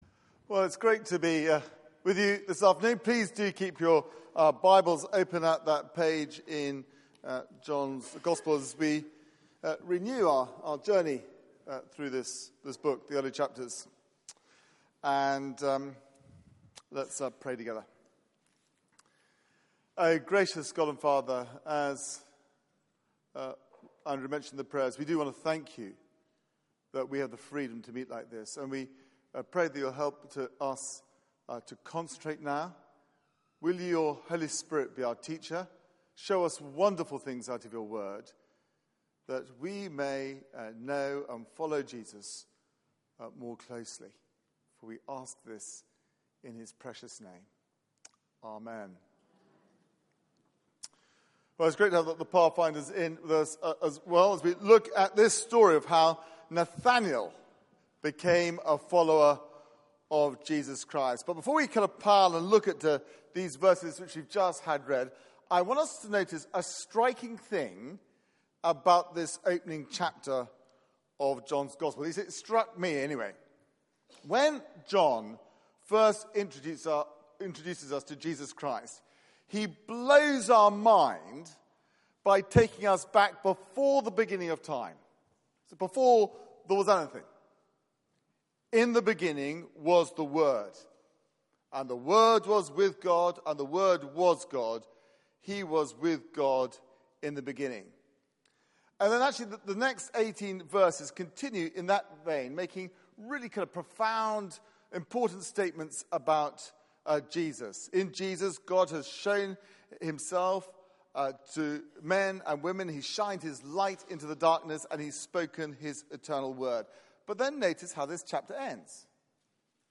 Media for 4pm Service on Sun 05th Jun 2016 16:00 Speaker
Series: This is Jesus Theme: Son of God, Son of Man Sermon Search the media library There are recordings here going back several years.